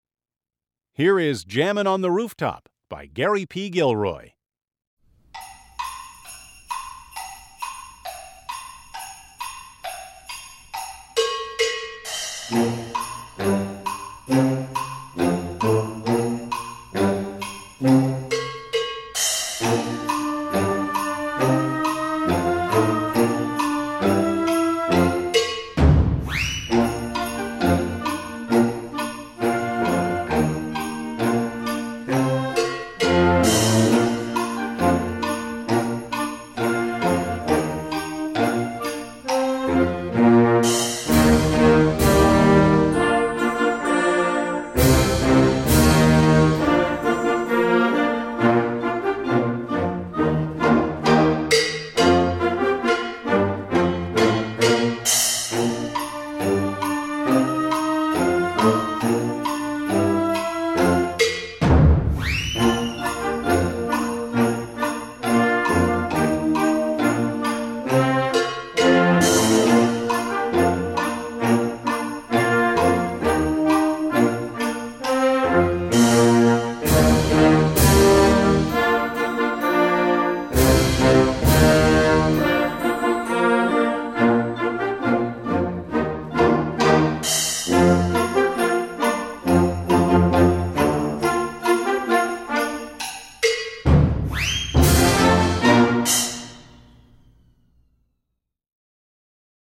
Voicing: Full Score